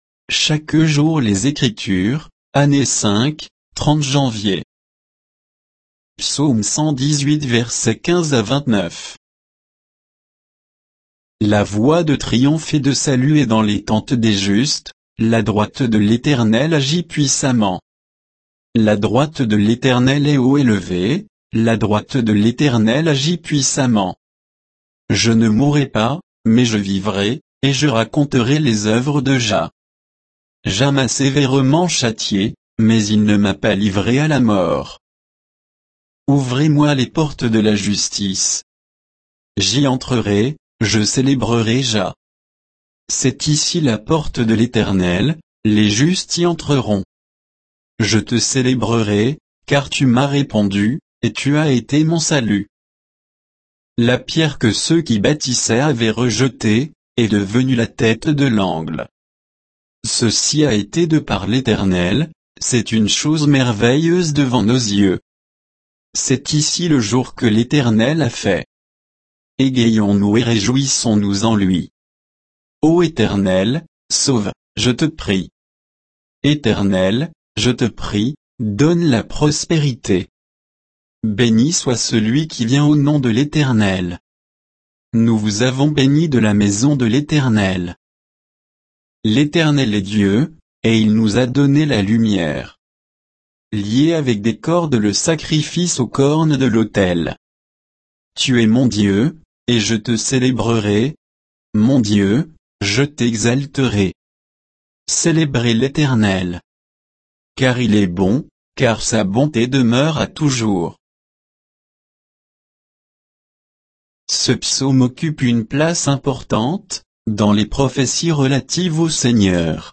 Méditation quoditienne de Chaque jour les Écritures sur Psaume 118, 15 à 29